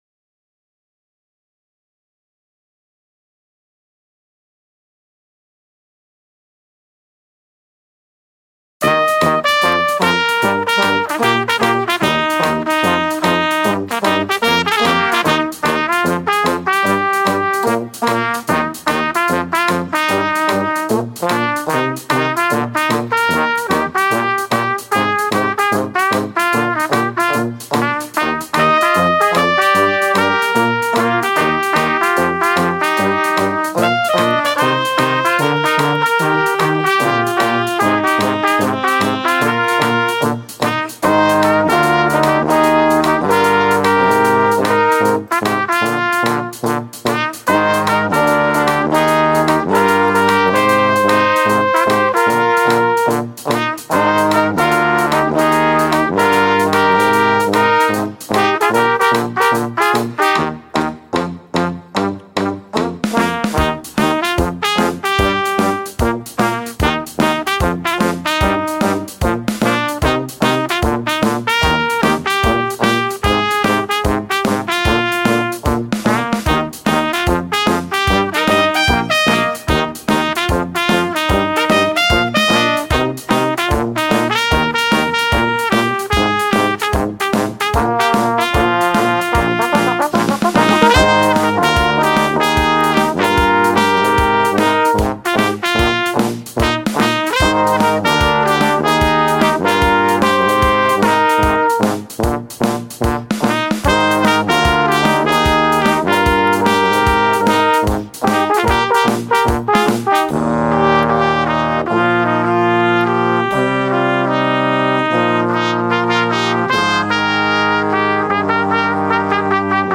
Studio Song #1! This is our first upload from the studio!
a variation of trumpet & mellophone melodies
This studio recording is a brass quartet transcription